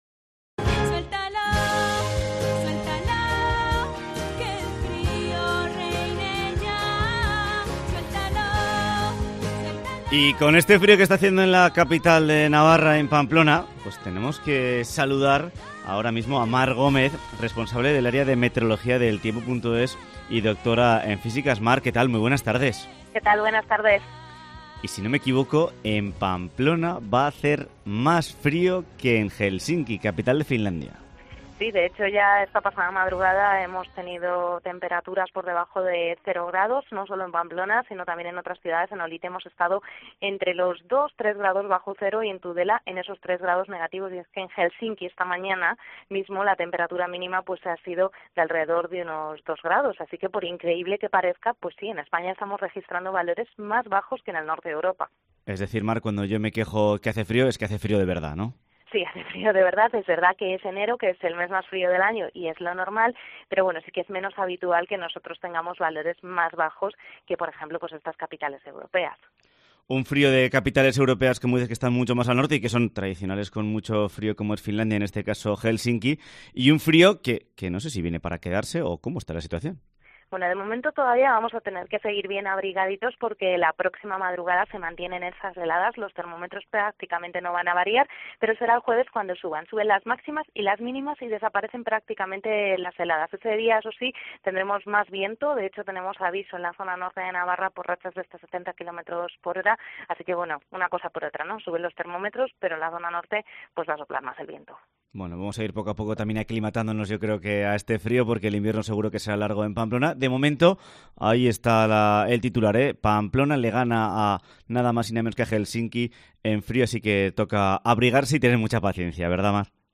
Programa de actualidad y entretenimiento que repasa la actualidad de la Comunidad Foral de Navarra.